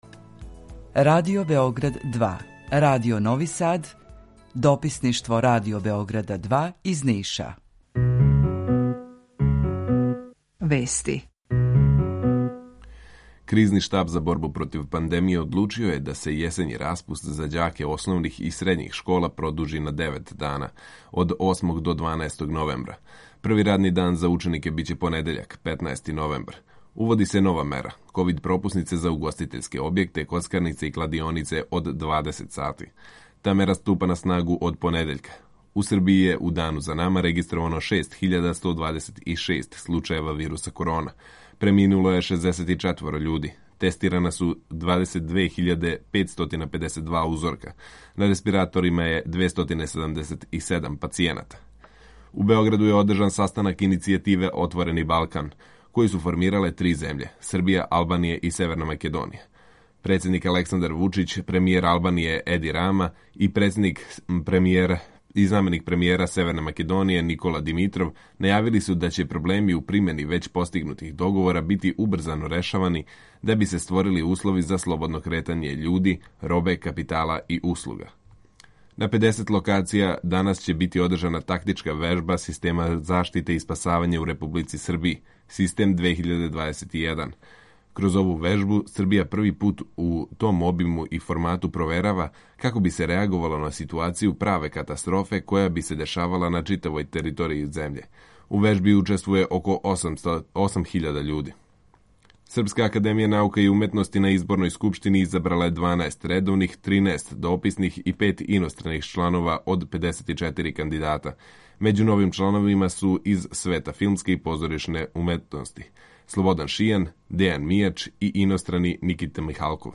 Емисију реализујемо заједно са студијом у Новом Саду и Радијом Републике Српске из Бања Луке
Јутарњи програм из три студија
У два сата, ту је и добра музика, другачија у односу на остале радио-станице.